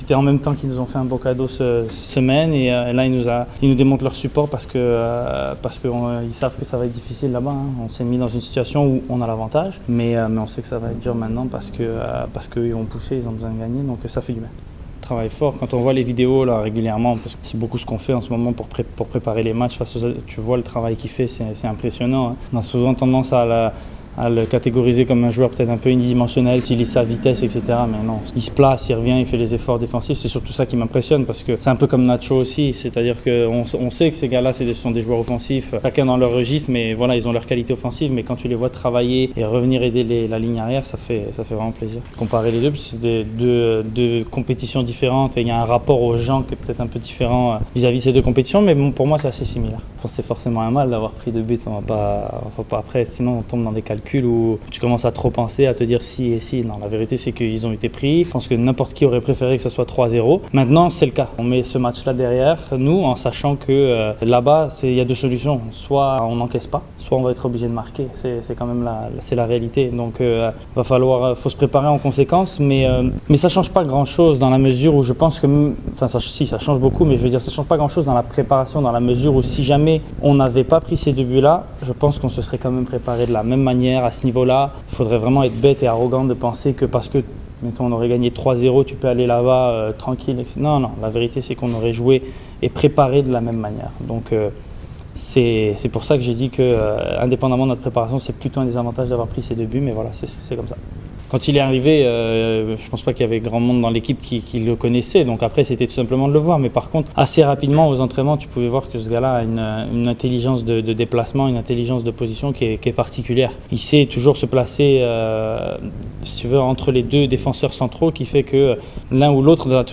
Le interviste dopo l’allenamento di oggi –